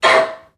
NPC_Creatures_Vocalisations_Robothead [10].wav